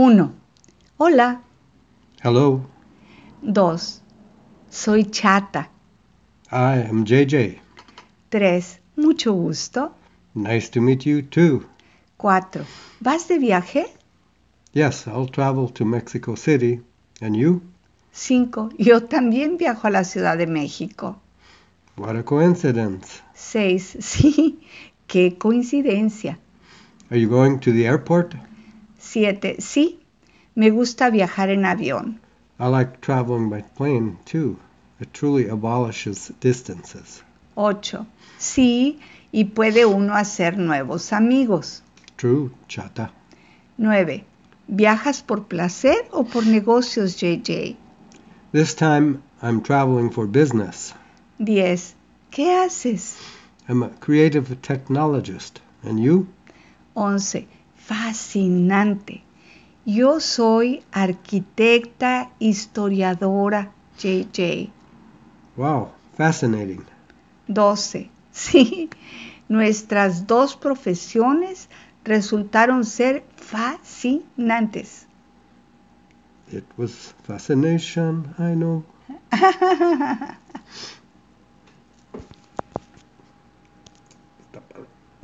Dialogue Lecture# 01
Lesson-1-Dialogue-SpanishToGo.mp3